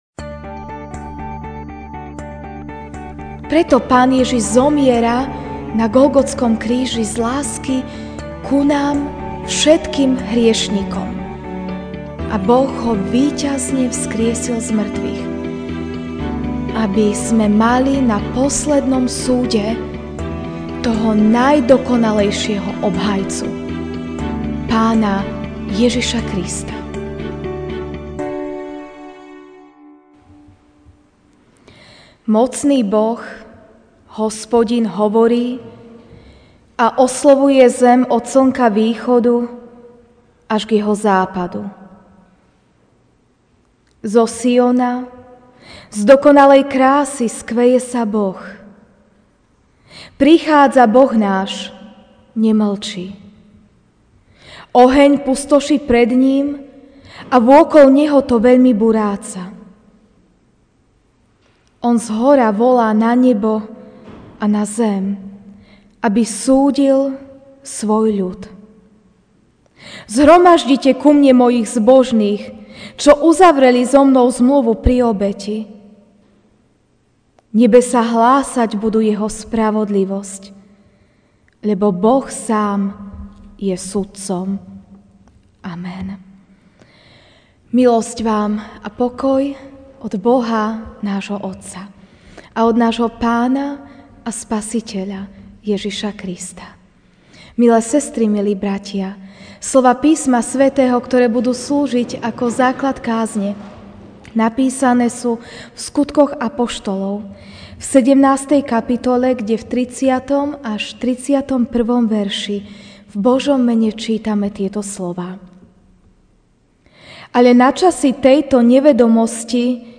Večerná kázeň: Pán Ježiš je naším obhajcom (Sk 17, 30-31)